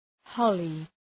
Προφορά
{‘hɒlı}